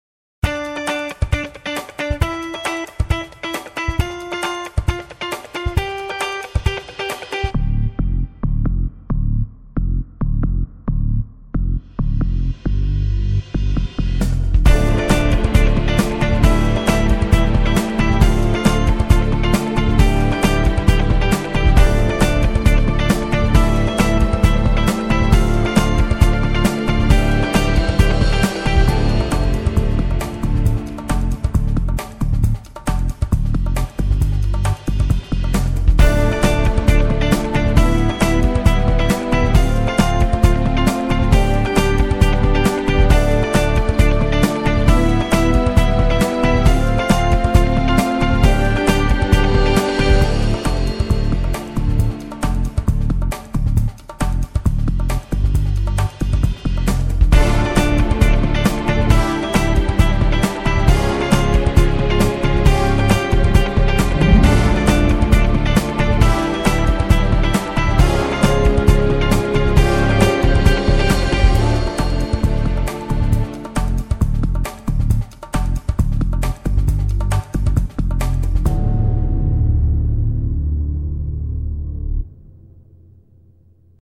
Petit exercice : 3 accompagnements différents :
Pour se faire, j’utilise un loop de guitare Funky (qui est, je trouve, plus approprié à la période estivale que nous vivons en ce moment, ambiance camping, boite de night etc…).
Ensuite j’effectue trois passages avec le même type d’orchestration (Basse + batterie RMX + accords plaqués de cordes et de cors), mais avec trois harmonisations différentes :
• 1er passage : harmonisation plutôt classique, discrète, douce…
• 2ème passage : plus osé, avec un mouvement en quintes parralèlles
• 3ème passage : plus pimenté, avec une petite modulation (on va changer de tonalité un court instant, alors que la montée des 4 notes ne change pas).